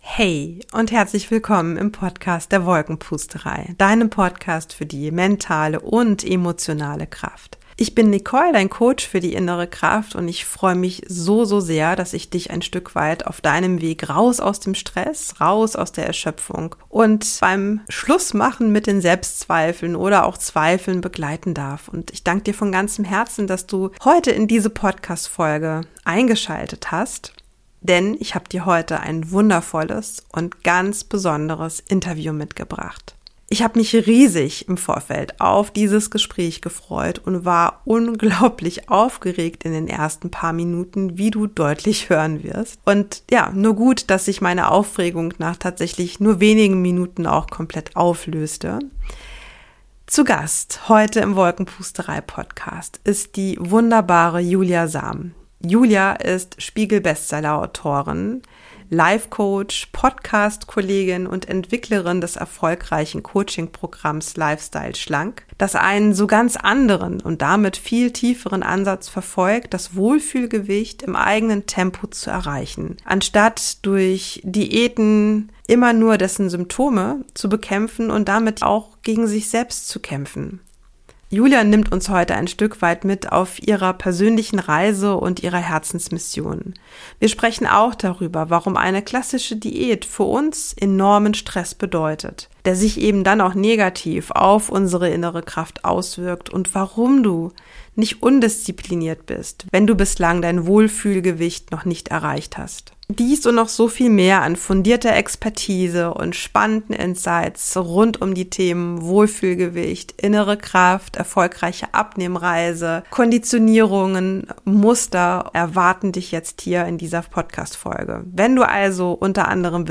#59 Raus aus dem inneren Kampf: Abnehmen ohne Selbstsabotage - Interview